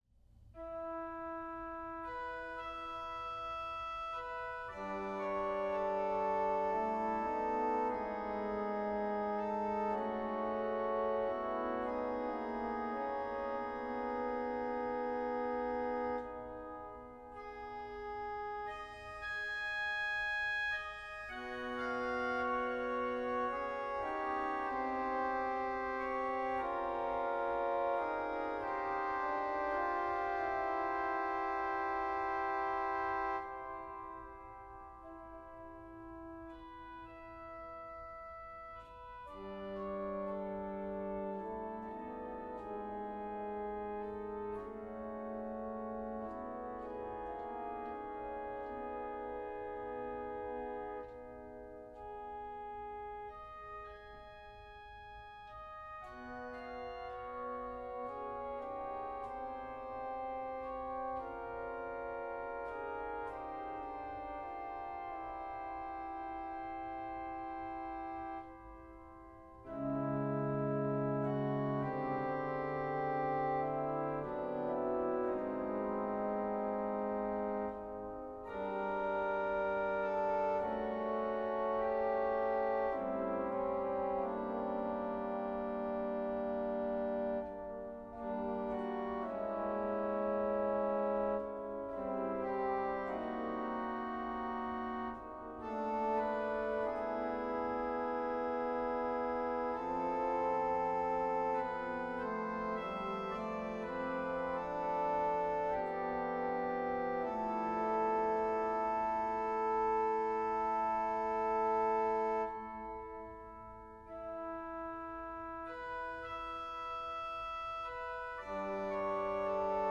(Adagio tranquillo)